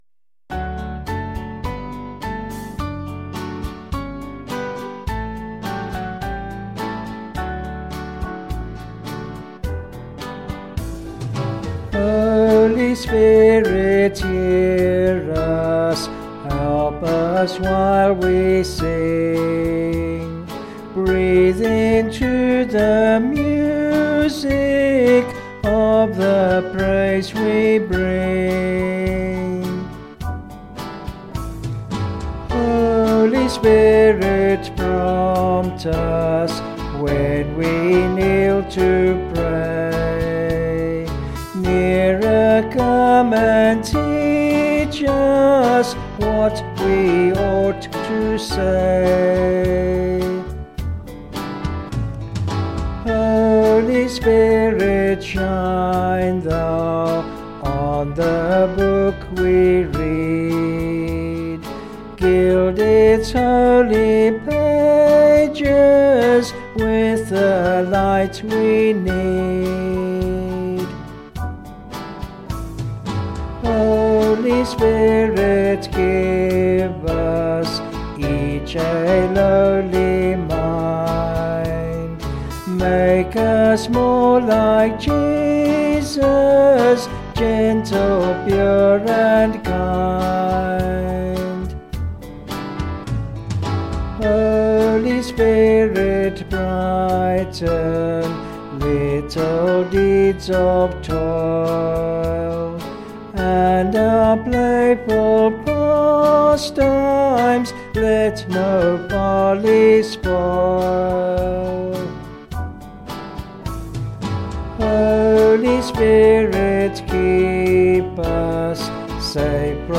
Vocals and Band   264.7kb Sung Lyrics